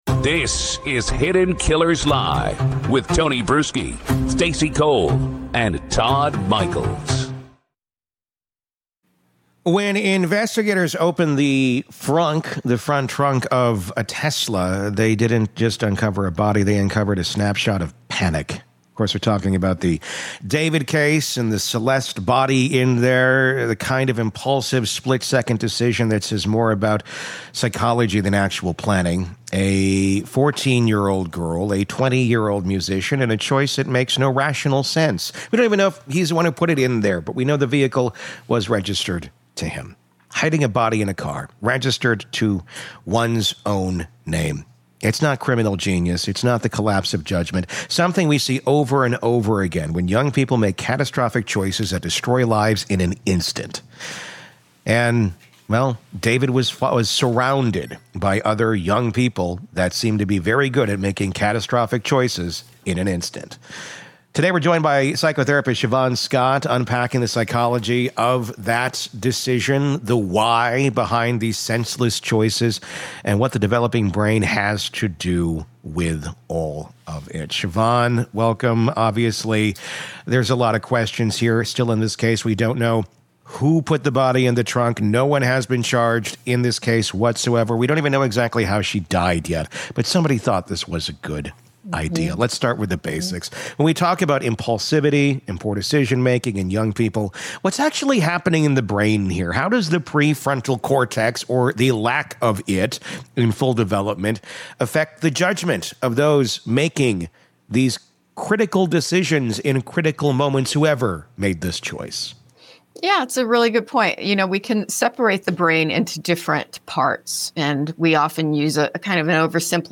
If you’ve ever wondered why some people make the worst decisions imaginable under pressure, this conversation will change how you think about crime, youth, and consequence.